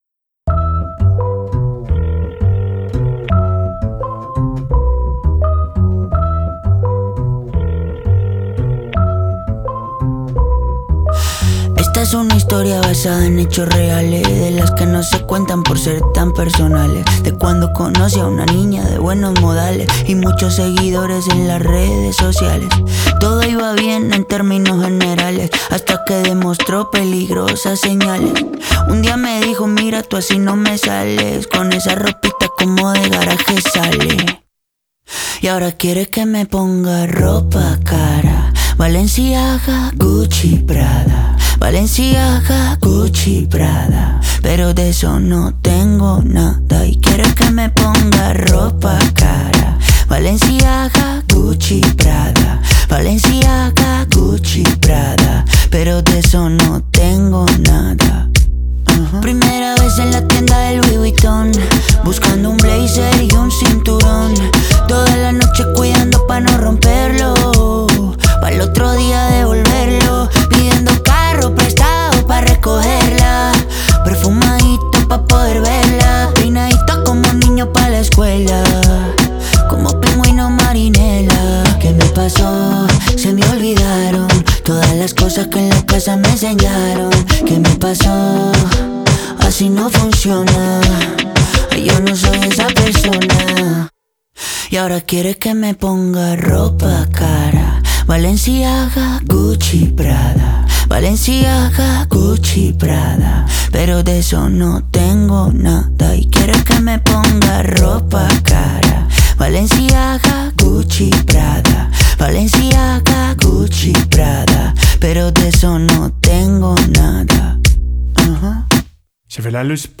это яркая и мелодичная песня колумбийского певца